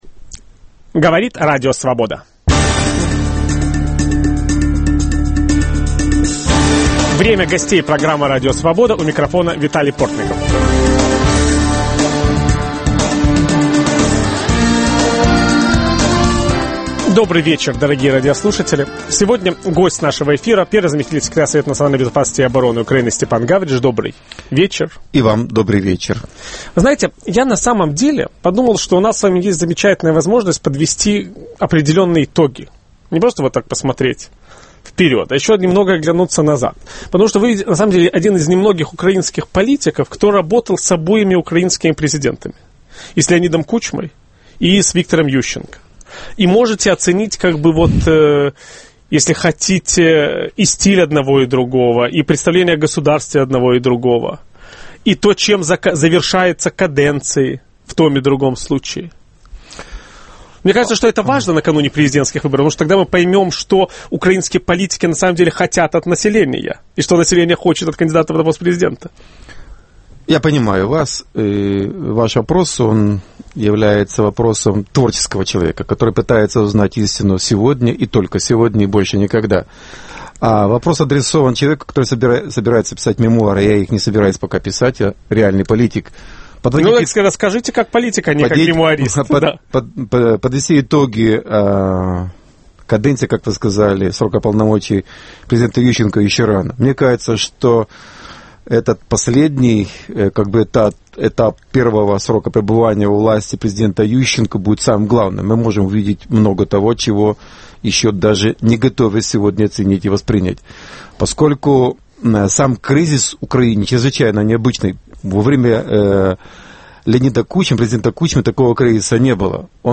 Гость киевской студии Радио Свобода – первый заместитель секретаря Совета национальной безопасности и обороны Украины Степан Гавриш.